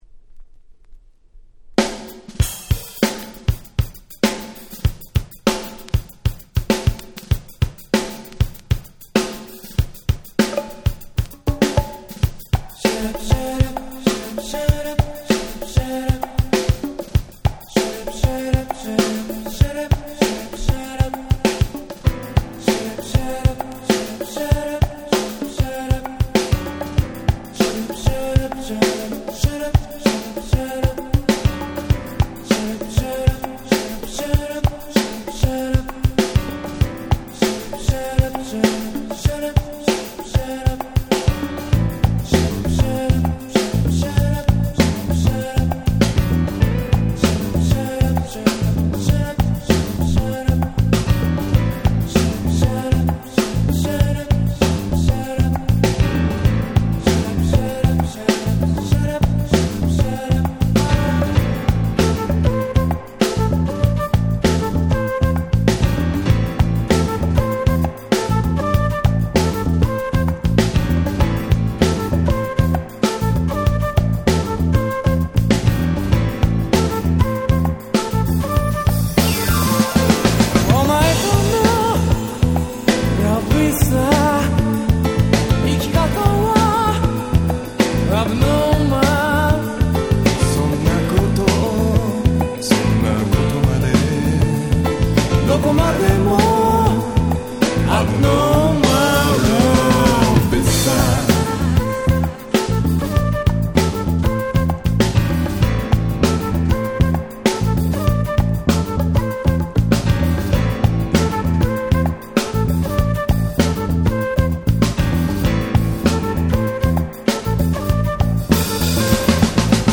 91' Very Nice J-Pop !!
いわゆる「渋谷系」の系譜で語られることの多い彼ら。